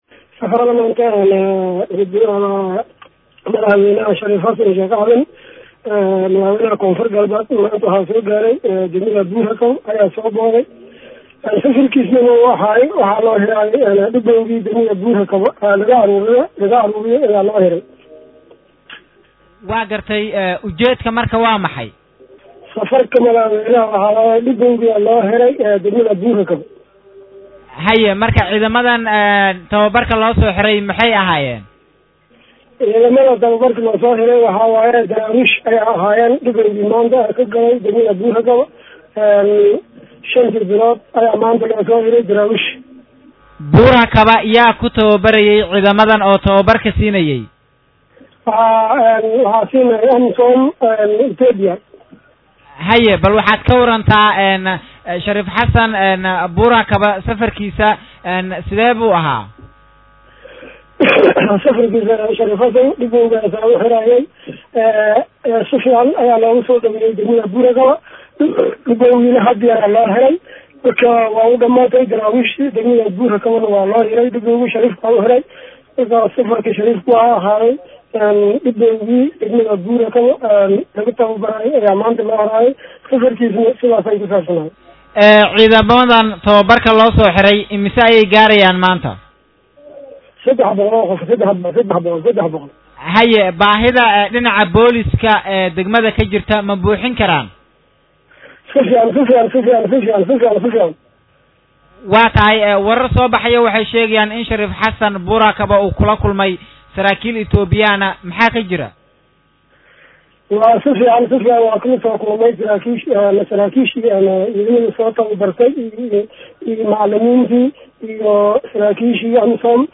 Halkan Ka Dhageyso Codka Gudoomiyha Degmada Buurhakaba